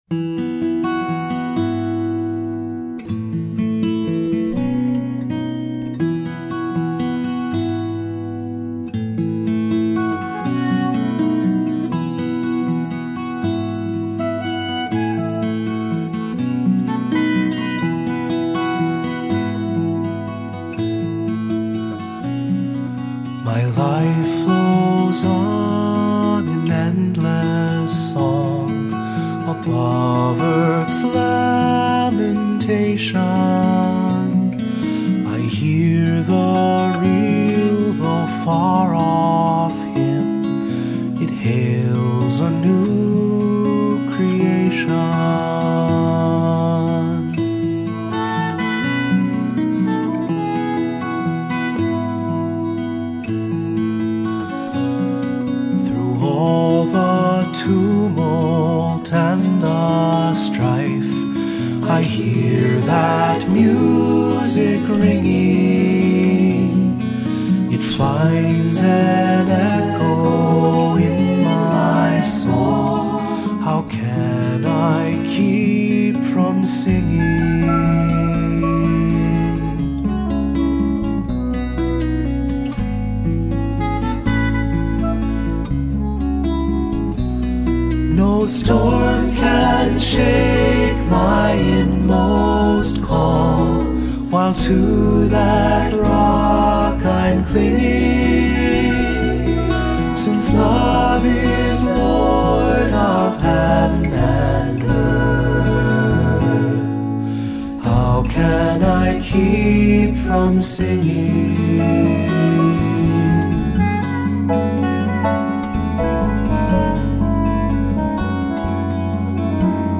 Accompaniment:      Keyboard, Cello
Music Category:      Christian
For cantor or soloist.